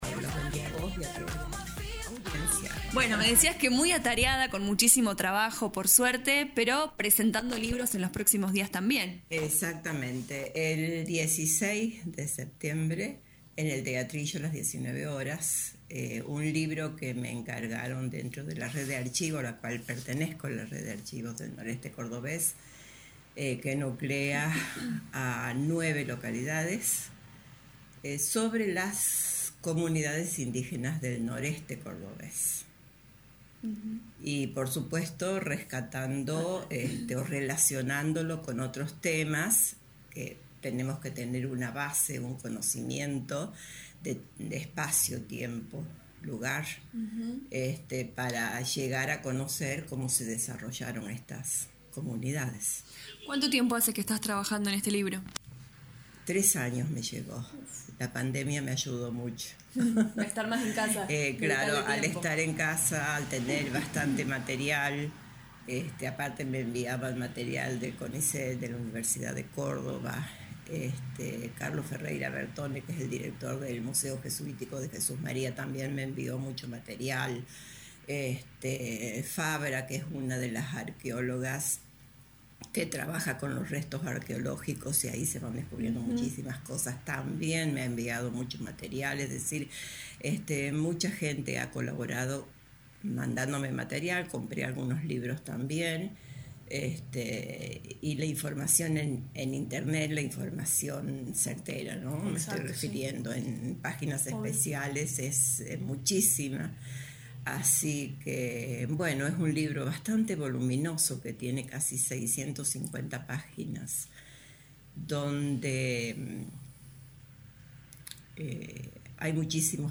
Lo anunció en diálogo con LA RADIO 102.9 FM